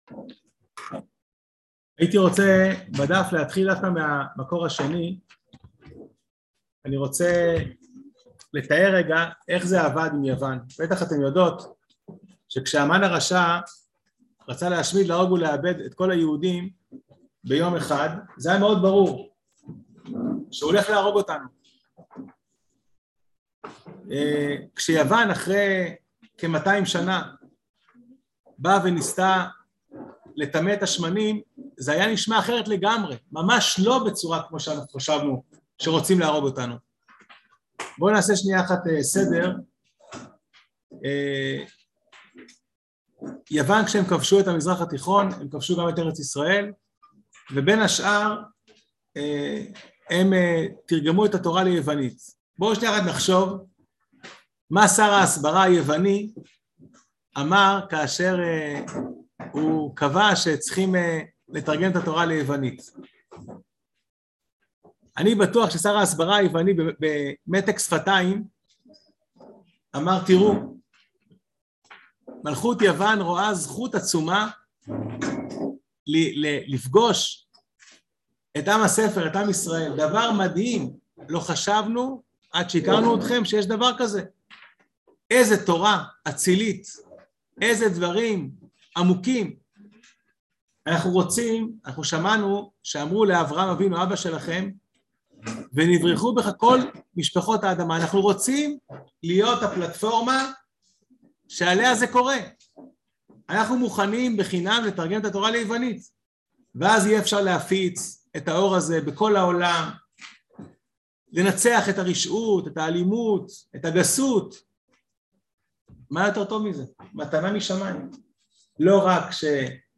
כשנכנסו יוונים להיכל | יום עיון לחנוכה תשפ"ב | מדרשת בינת